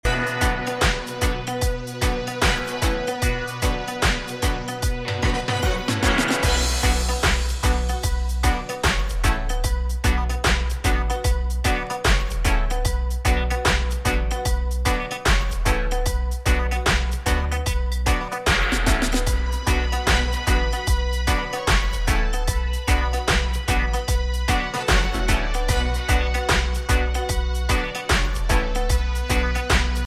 Reggae Ska Dancehall Roots